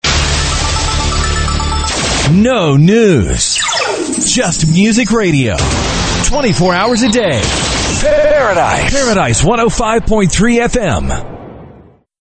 RADIO IMAGING / TOP 40